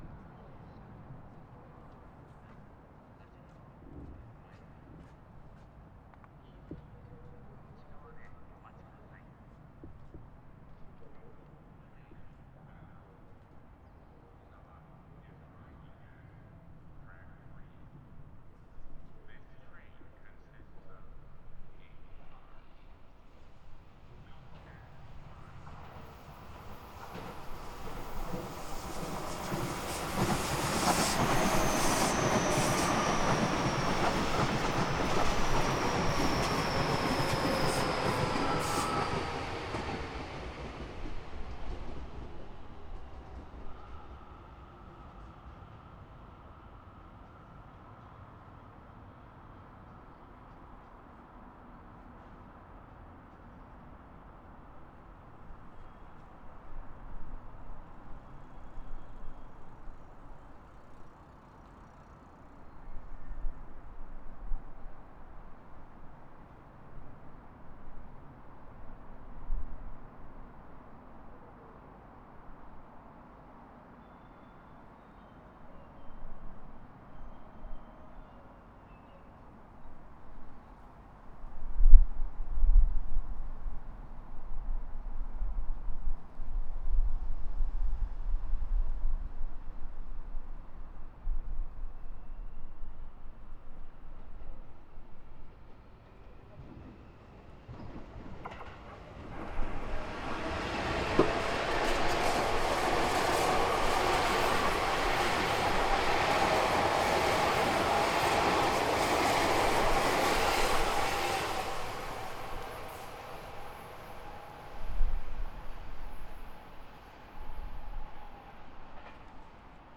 下り電車通過。
続いて上り電車通過。
H5studio　内蔵マイク＋ZOOM　ヘアリーウィンドスクリーン WSU-1